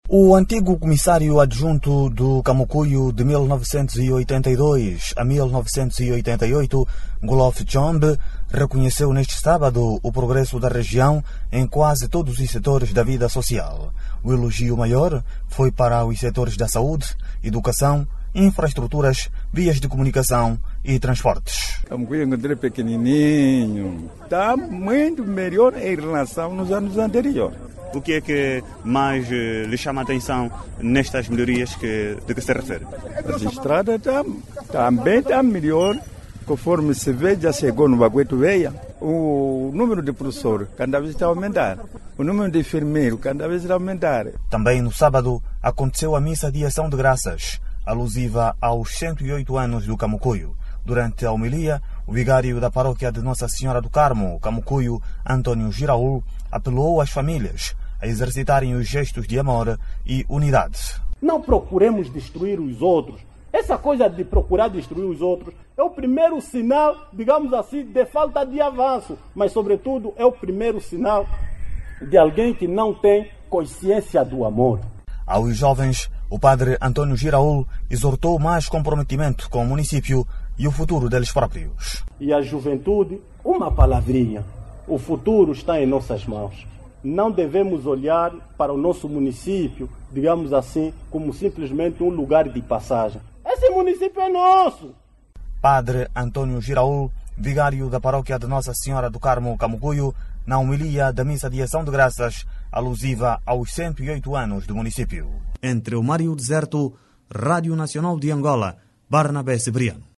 O município do Camucuio, no Namibe, está a ganhar várias infraestruturas de impacto social que contribuem para o desenvolvimento da região. Estradas, escolas e hospitais são algumas infraestruturas apontadas pela população, que estão a mudar a imagem de Camucuio. Ouça no áudio abaixo toda informação com a reportagem